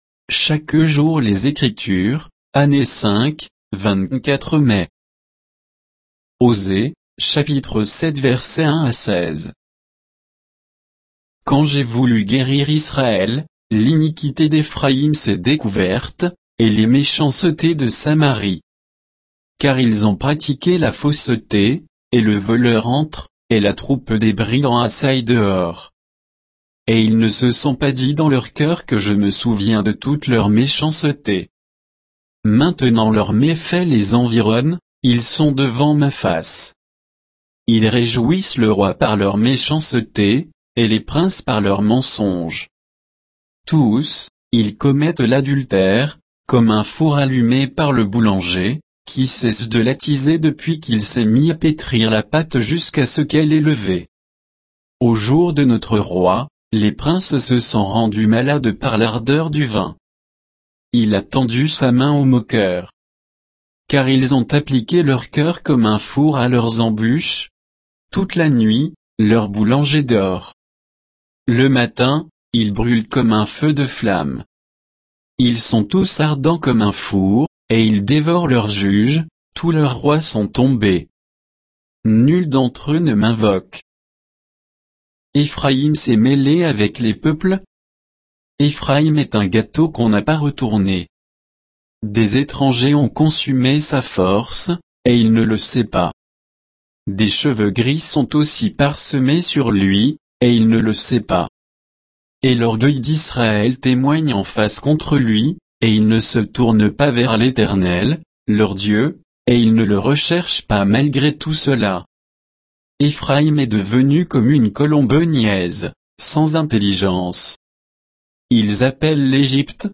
Méditation quoditienne de Chaque jour les Écritures sur Osée 7